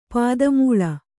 ♪ pāda mūḷa